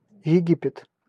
Ääntäminen
US : IPA : /ˈiː.dʒɪpt/